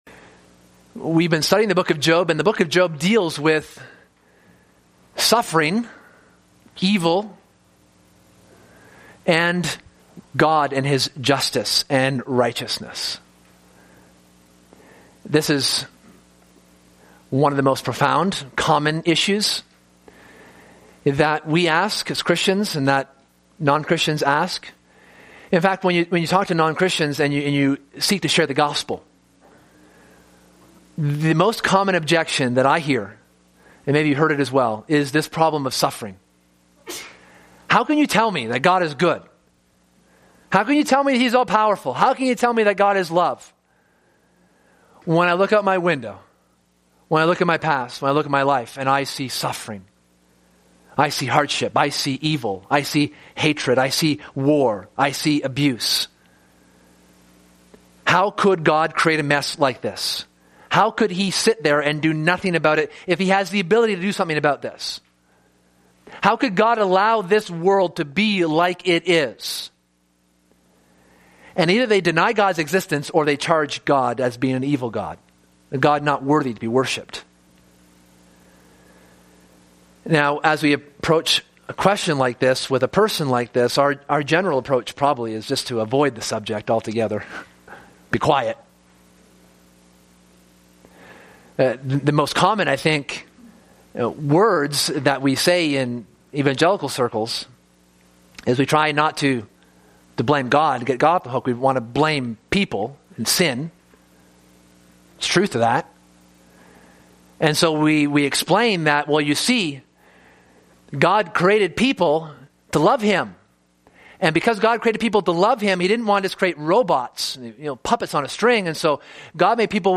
This book, and thus this sermon series, explores the nature and character of God.